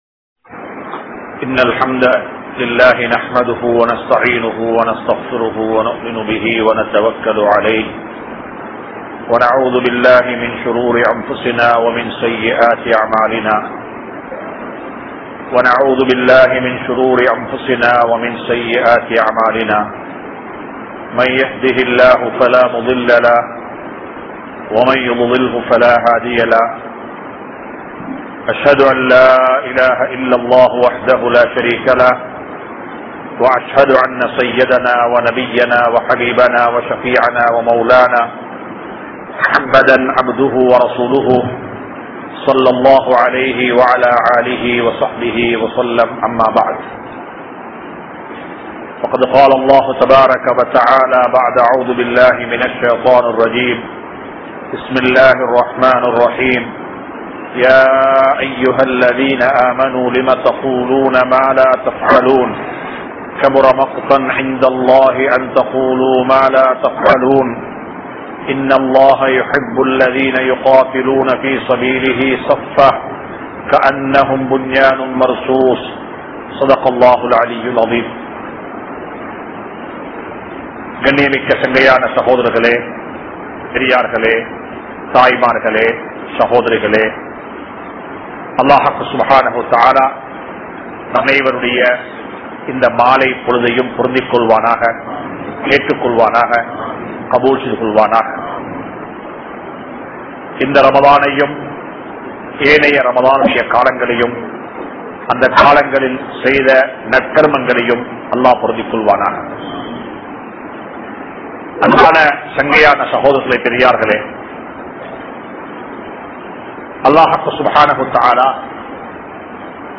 Halaal`Aana Ulaipu(ஹலாலான உழைப்பு) | Audio Bayans | All Ceylon Muslim Youth Community | Addalaichenai
Negombo, Grand Jumua Masjith